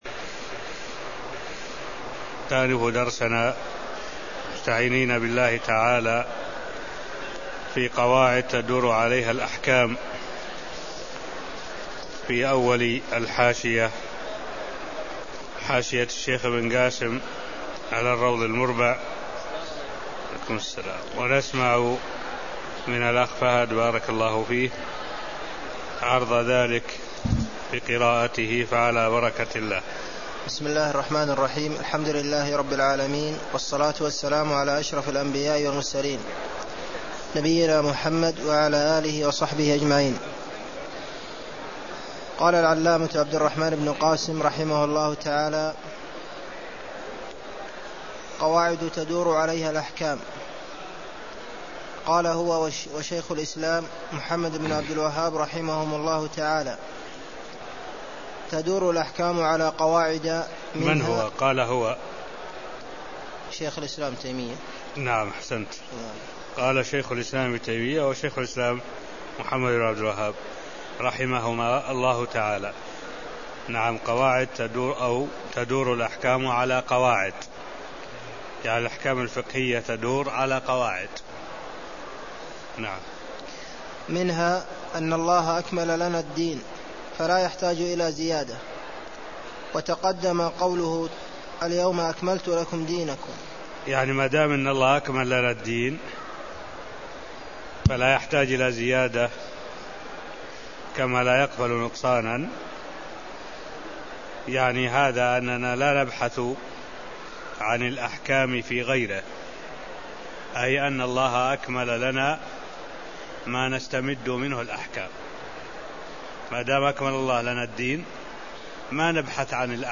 المكان: المسجد النبوي الشيخ: معالي الشيخ الدكتور صالح بن عبد الله العبود معالي الشيخ الدكتور صالح بن عبد الله العبود أصول وقواعد وتنبيهات (0002)